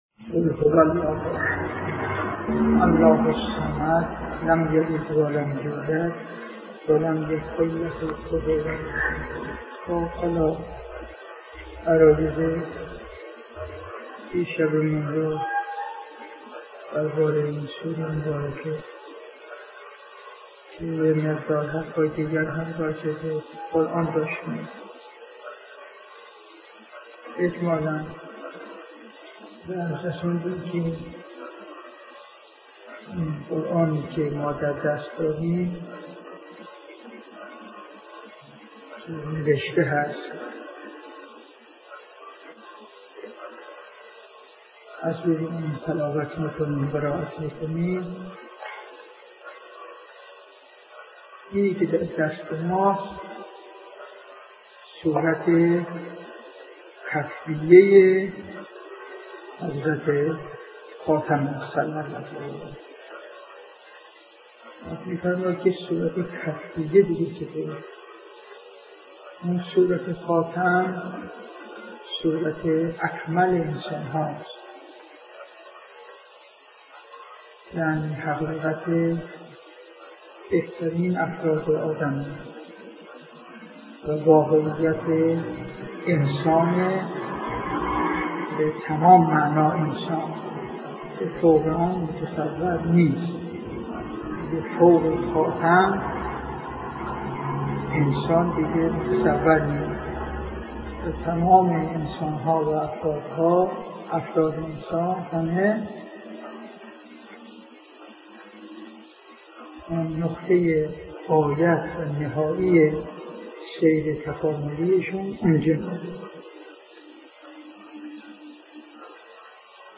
تفسیر سوره توحید توسط علامه حسن زاده - جلسه اول